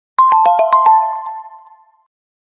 Звуки сообщений
На данной странице вы можете прослушать онлайн короткие звуки уведомлений для sms на  телефон android, iPhone и приложения.